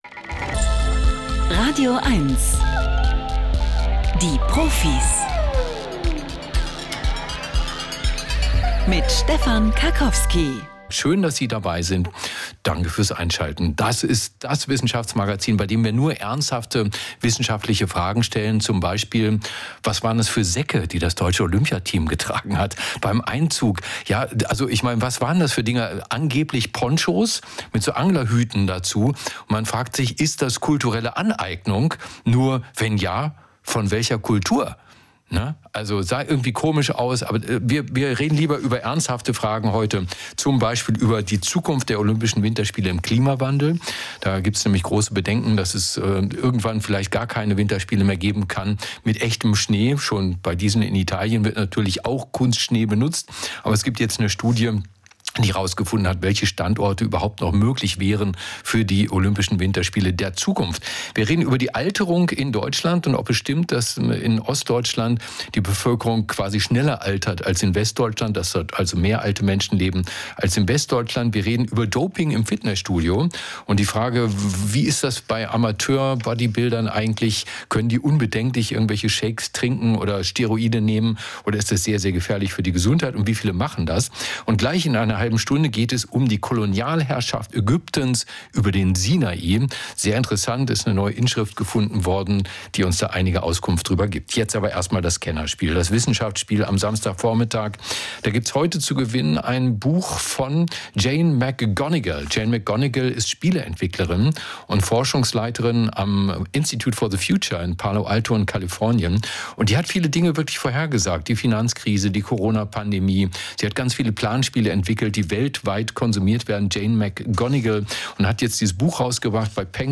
Leise Töne, starker Zusammenhalt: Wir sprechen mit einer Verhaltensbiologin über die einzigartige Kommunikation der Erdmännchen.